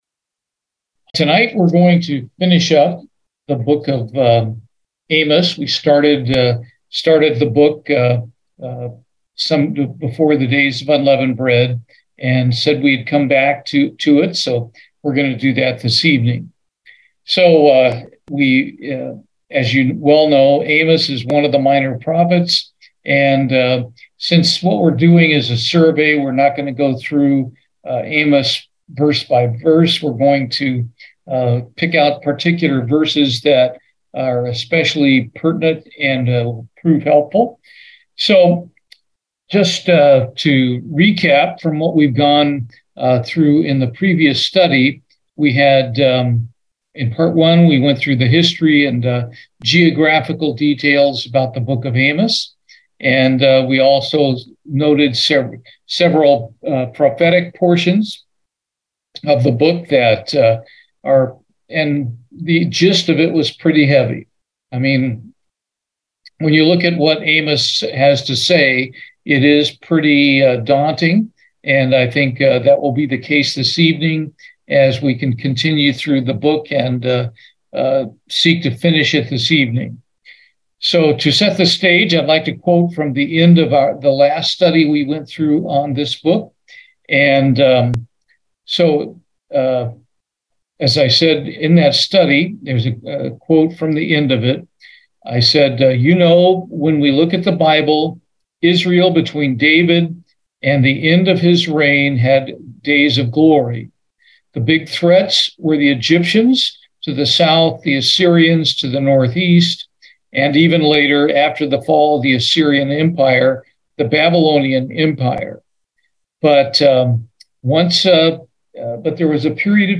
Bible Study, Amos, part 2